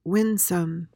PRONUNCIATION: (WIN-suhm) MEANING: adjective: Pleasing or charming, especially in a childlike or innocent manner.